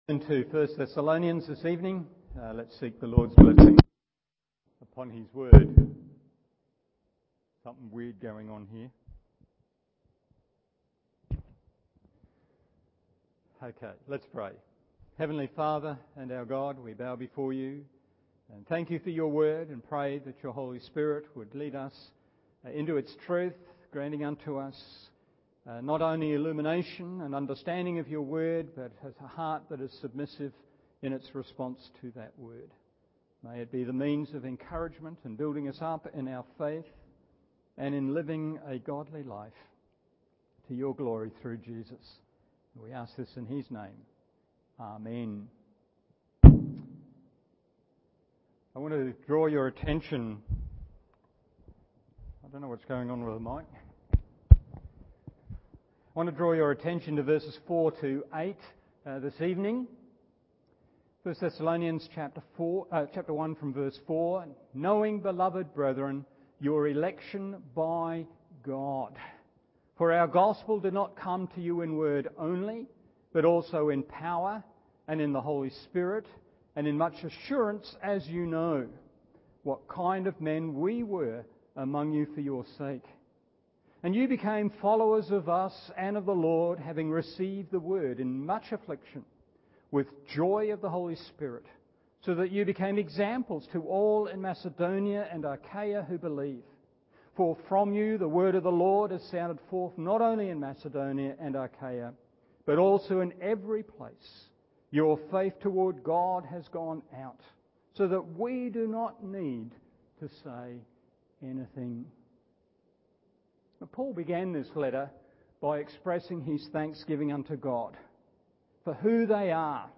Evening Service 1 Thessalonians 1:4-8 1. In Us 2. On Us 3.